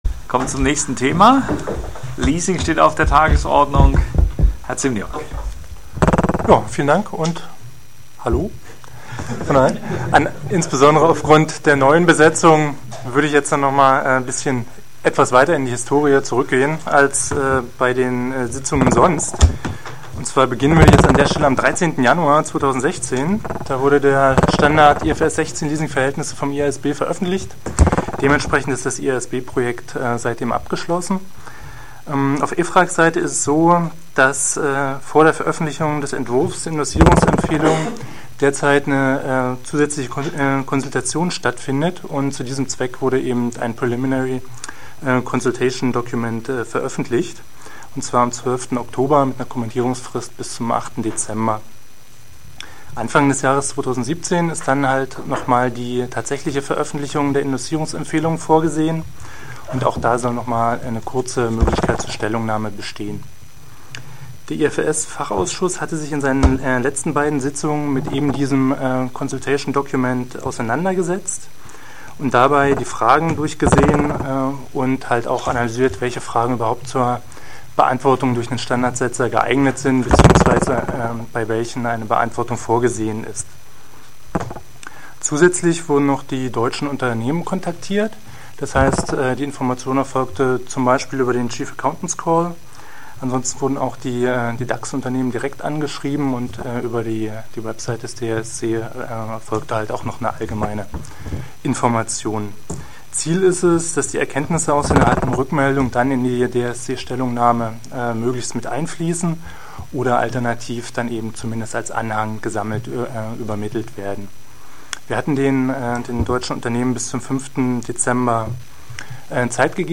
55. Sitzung IFRS-FA • DRSC Website